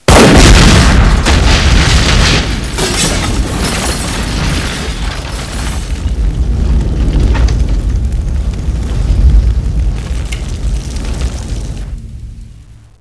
c4_explode1.wav